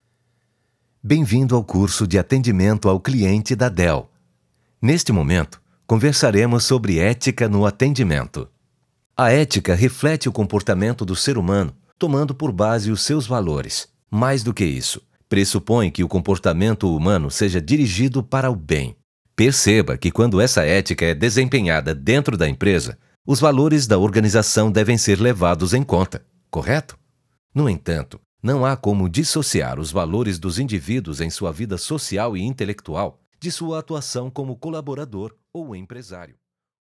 offering a confident, versatile baritone voice
E-learning
Neumann tlm103, Audio Technica AT 4033, Avalon vt737SP, Audient Id14, Yamaha HS50, Mac Mini M1